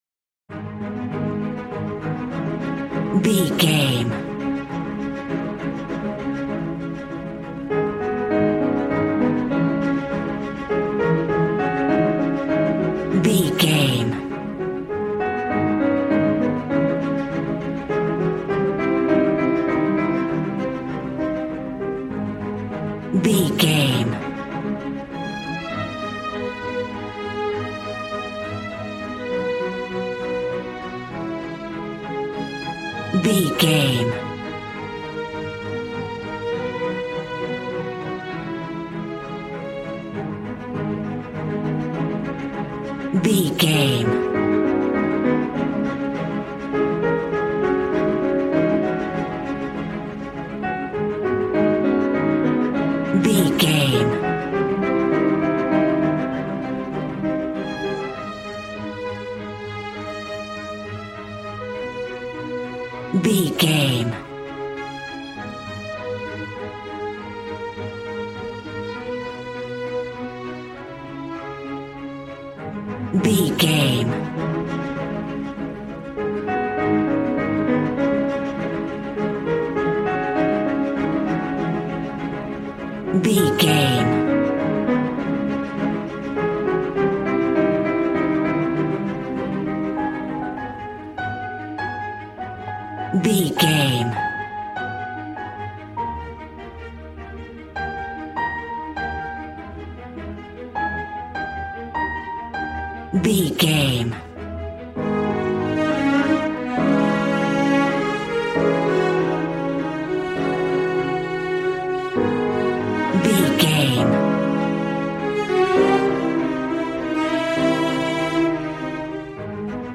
Regal and romantic, a classy piece of classical music.
Aeolian/Minor
A♭
regal
strings
brass